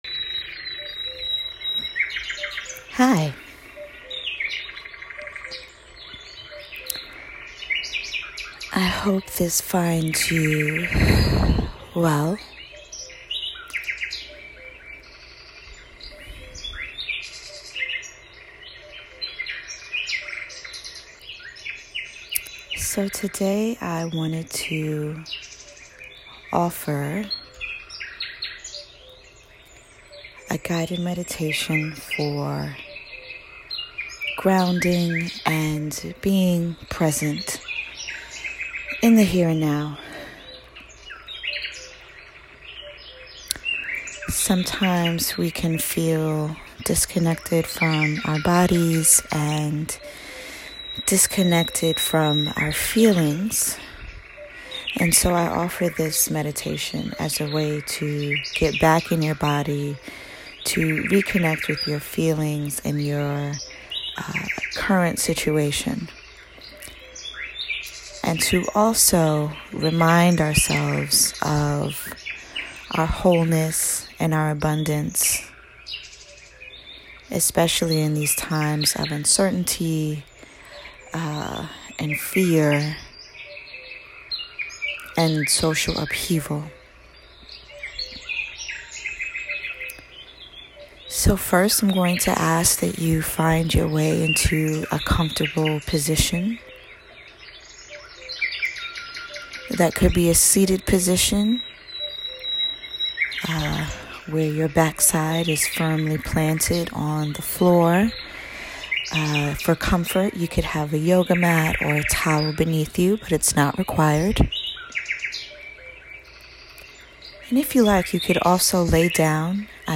This meditation has three components: sound, breath, and mantra.
grounding-meditation.m4a